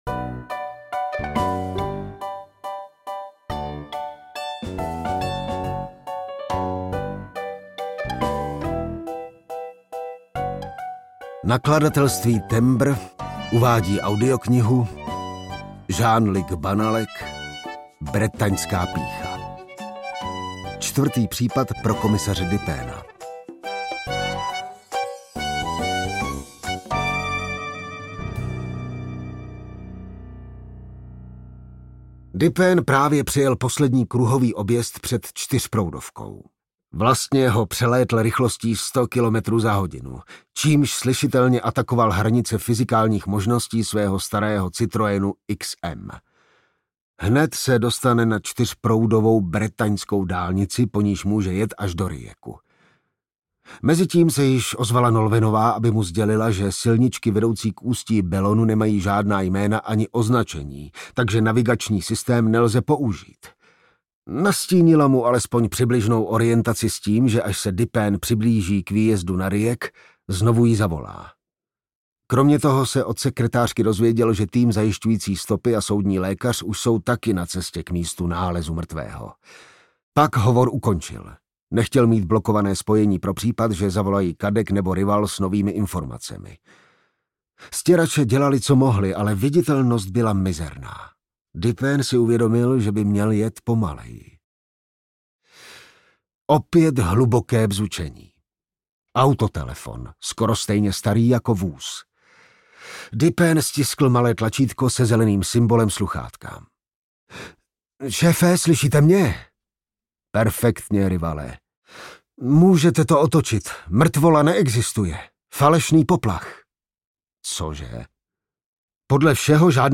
Bretaňská pýcha audiokniha
Ukázka z knihy
bretanska-pycha-audiokniha